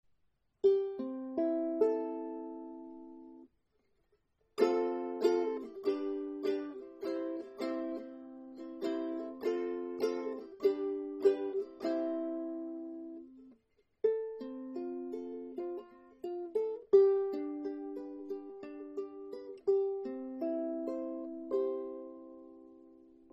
Meine Soundbeispiele sind alle am gleichen Tag mit einem Zoom H2 aufgenommen.
Gespielt habe ich nur ein paar Akkorde.
Korpus massiv Sapele,   Aquila-Saiten